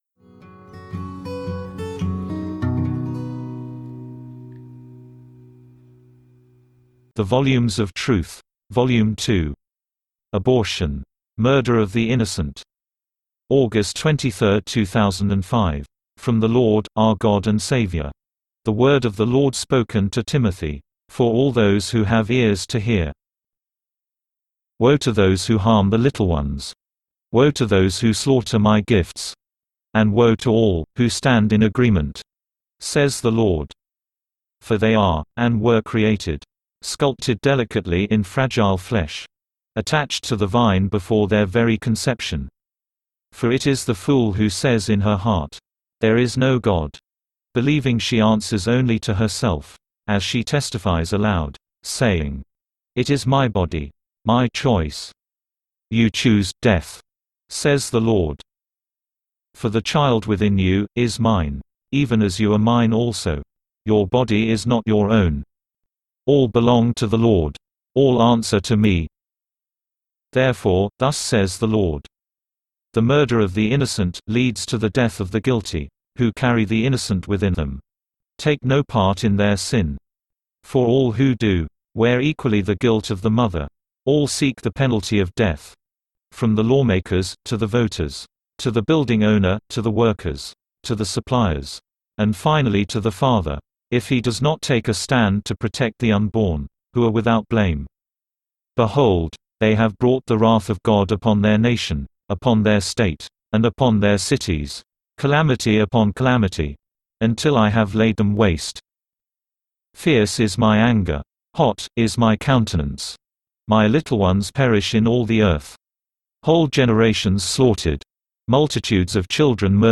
File:V2.017 Abortion-Murder of the Innocent (read by text-to-speech).mp3 - The Volumes of Truth
V2.017_Abortion-Murder_of_the_Innocent_(read_by_text-to-speech).mp3